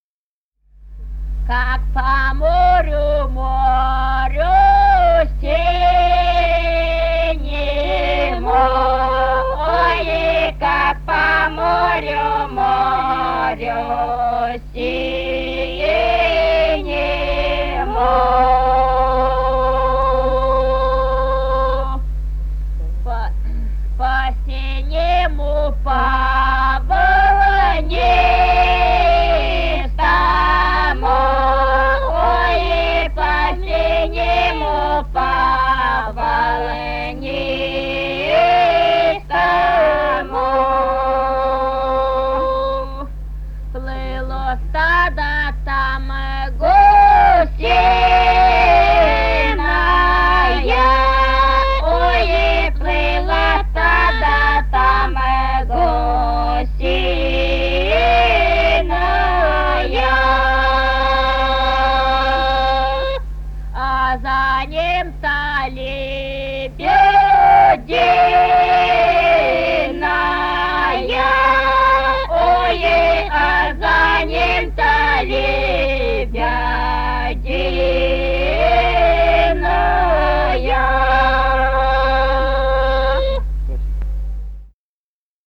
Русские народные песни Красноярского края.
«Как по морю, морю синему» (свадебная). с. Тасеево Тасеевского района.